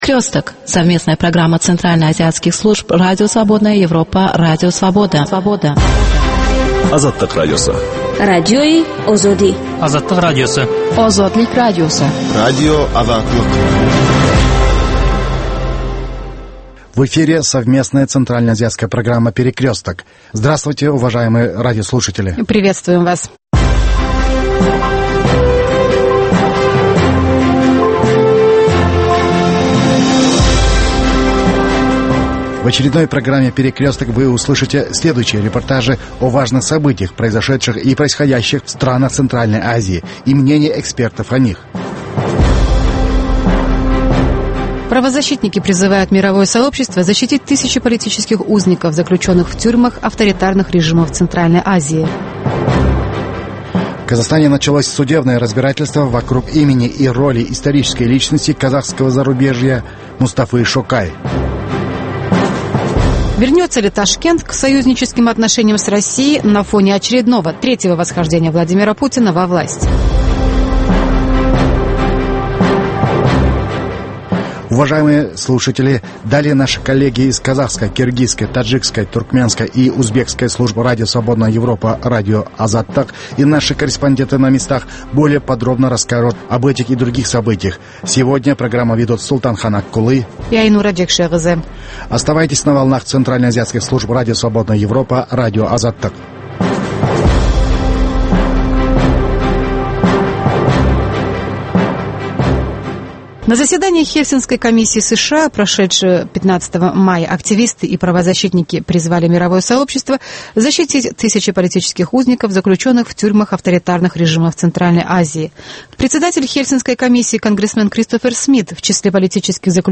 Новости стран Центральной Азии. Специальная программа на русском языке.